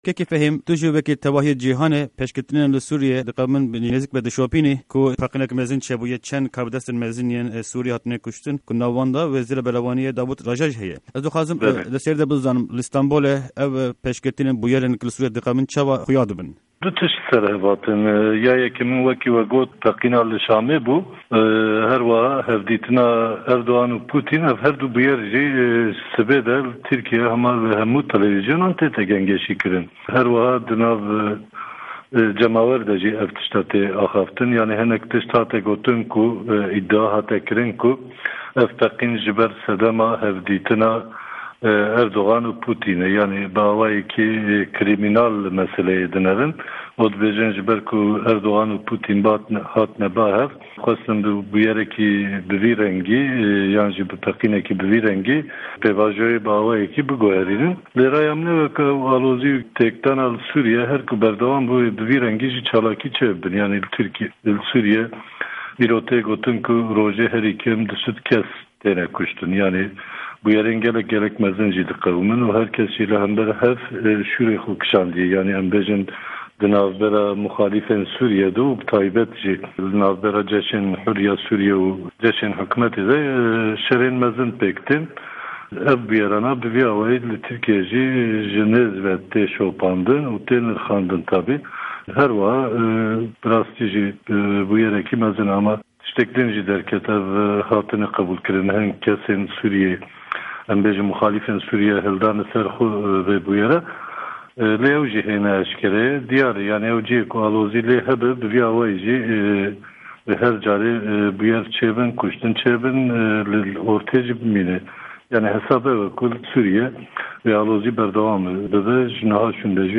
Hevpeyvîn_FI